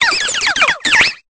Cri de Candine dans Pokémon Épée et Bouclier.